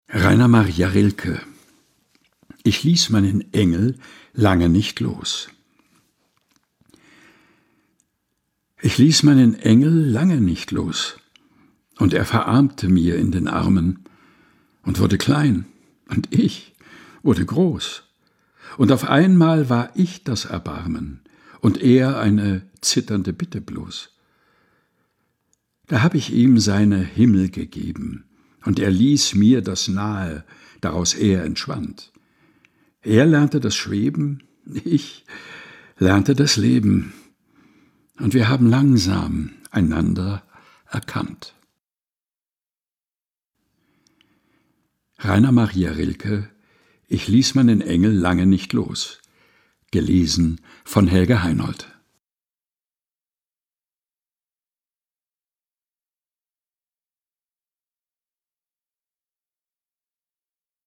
liest sie in seinem eigens zwischen Bücherregalen eingerichteten, improvisierten Studio ein